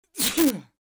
Sneezes Male
Sneezes Male.wav